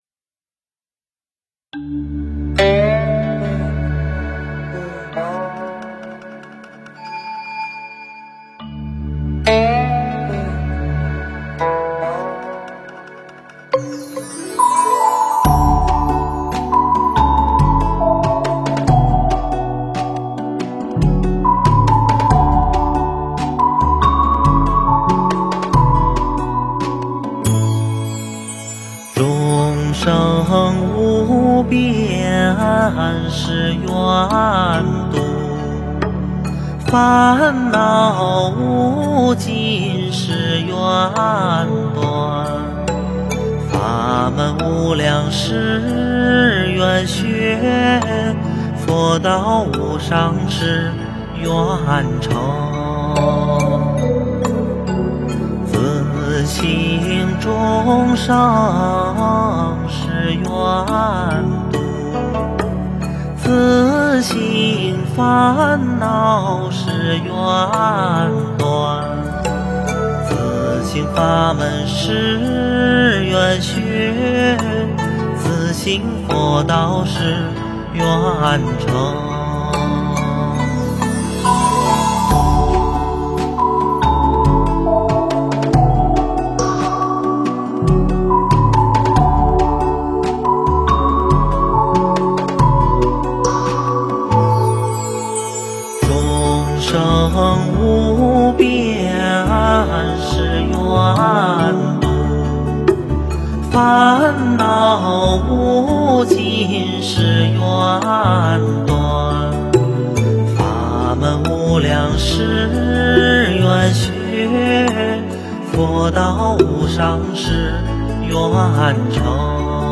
诵经
佛音 诵经 佛教音乐 返回列表 上一篇： 安逸忏心 下一篇： 观音灵感真言(梦授咒